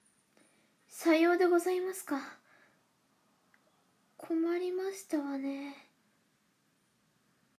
サンプルボイス おしとやか 【少女】